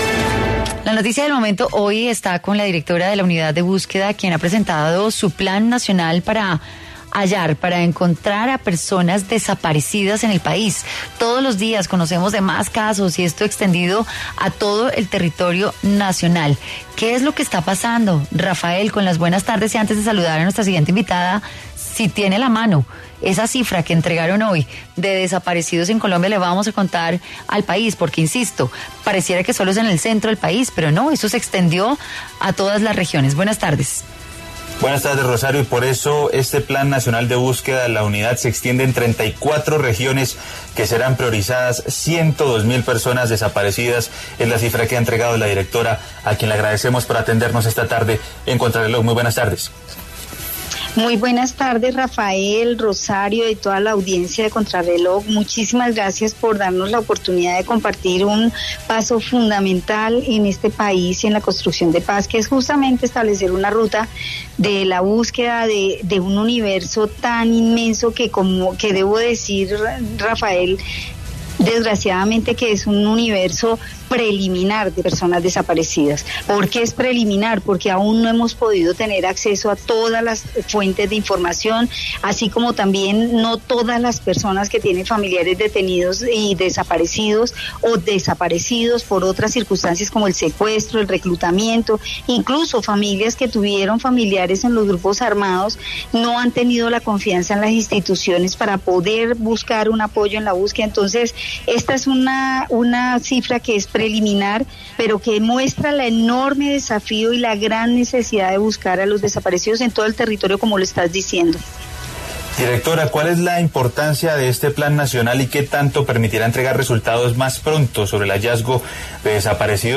En entrevista con Contrarreloj, la directora de la Unidad de Búsqueda de Desaparecidos, Luz Marina Monzón, se refirió al lanzamiento del plan nacional de búsqueda que adelantarán en 34 regiones, así como las críticas a la falta de colaboración del gobierno Duque en su labor.